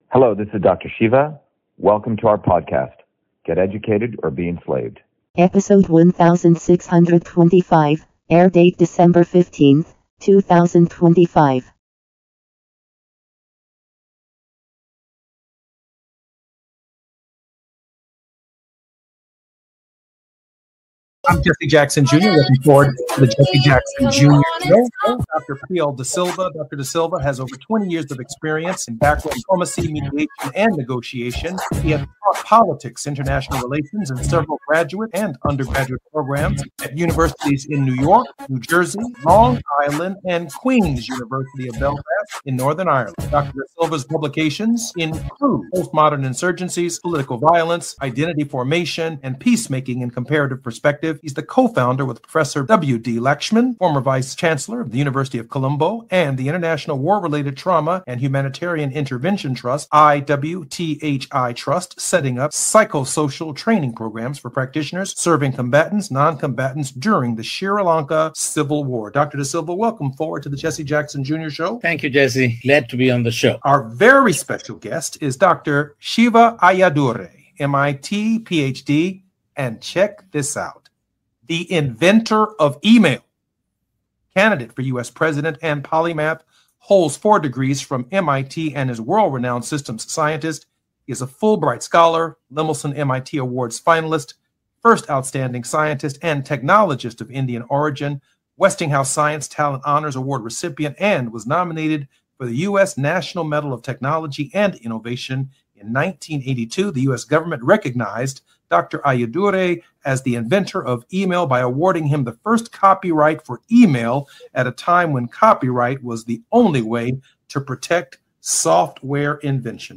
In this interview, Dr.SHIVA Ayyadurai, MIT PhD, Inventor of Email, Scientist, Engineer and Candidate for President, Talks about Get Off The Healthcare Plantation!